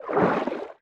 Sfx_creature_trivalve_swim_slow_01.ogg